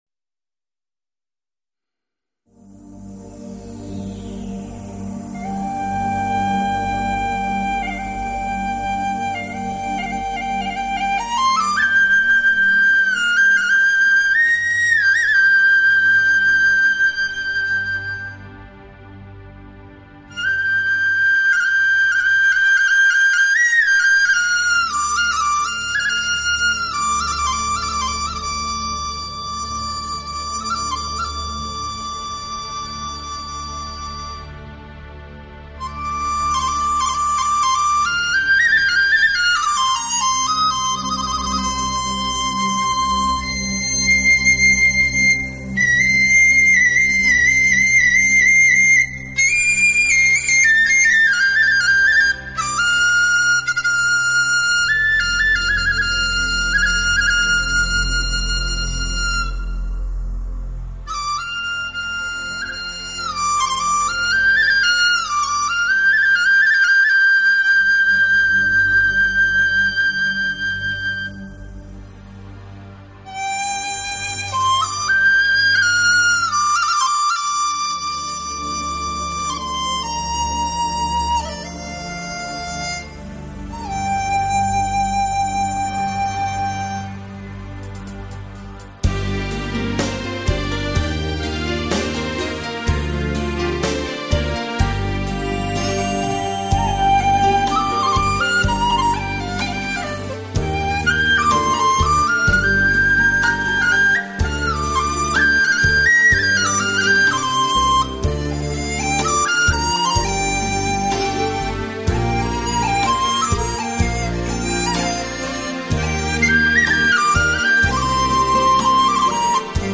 音乐类别:发烧/试音